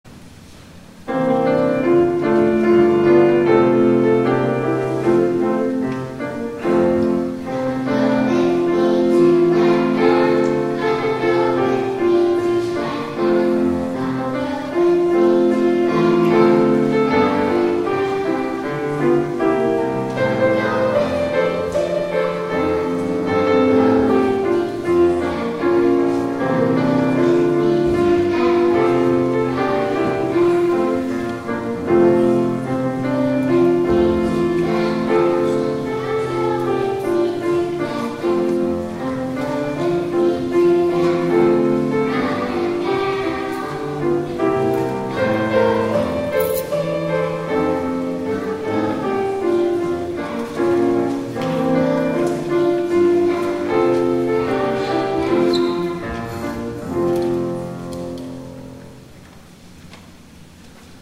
Come Go With Me (Cherub Choir)  Spiritual